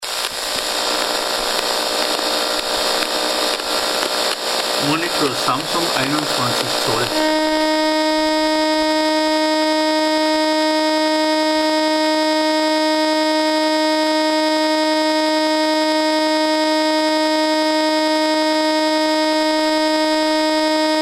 STÖRQUELLEN AUDIODATENBANK
Monitor Samsung SyncMaster 2233BW Low E-Field Netz im Betrieb 100-149